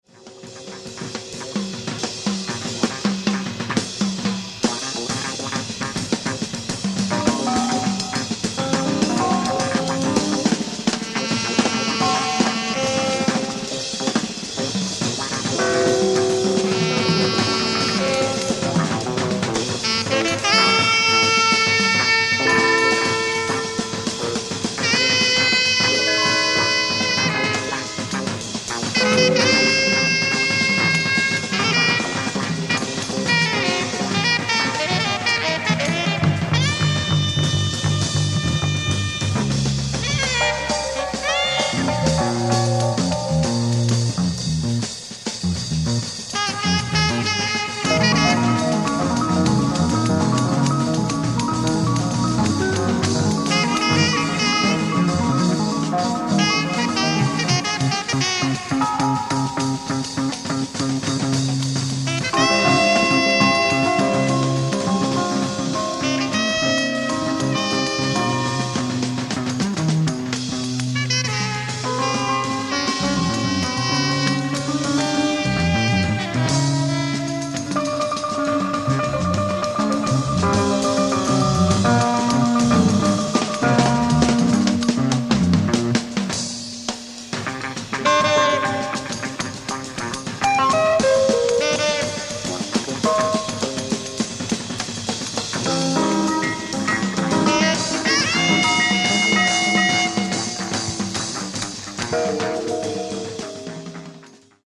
ライブ・アット・国際シュティフェスティバル、オッシアッハ、オーストリア 06/27/1971
新発掘のステレオ・サウンドボード音源！！
※試聴用に実際より音質を落としています。